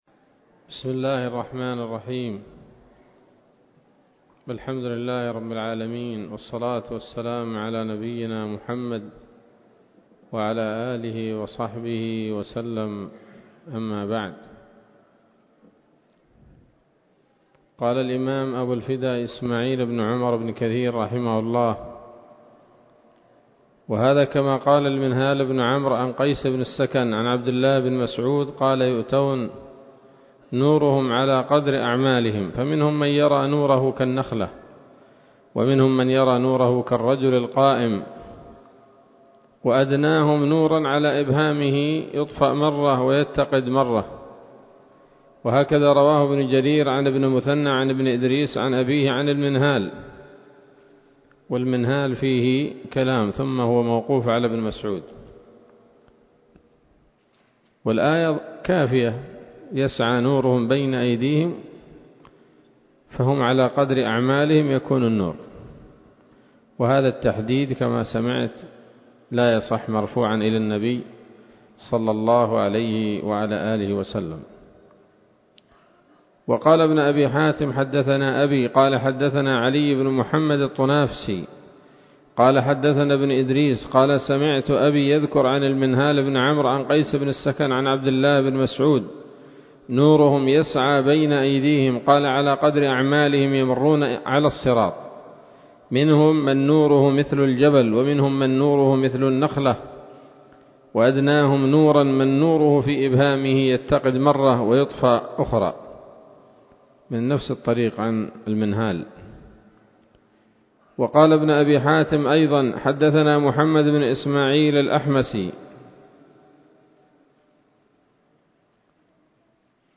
الدرس الثاني والعشرون من سورة البقرة من تفسير ابن كثير رحمه الله تعالى